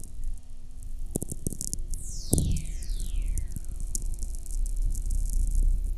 Heidelberg, Germany